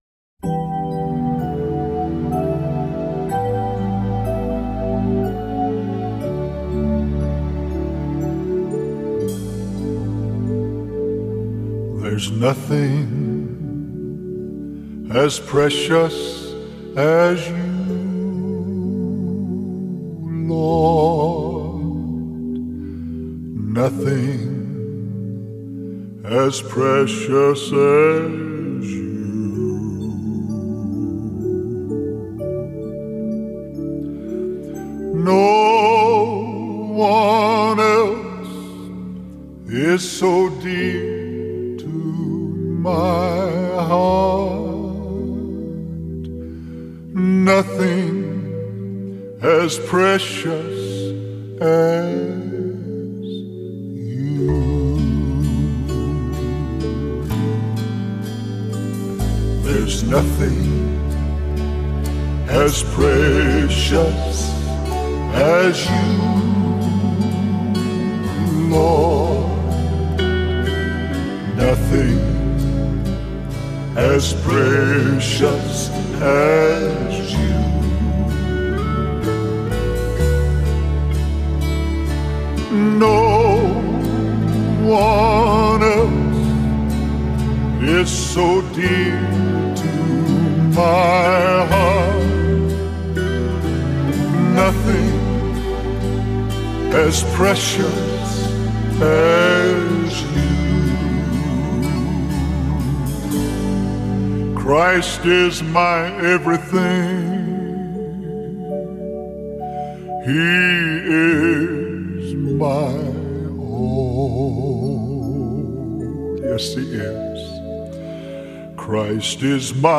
blends soulful melodies with raw, heartfelt lyrics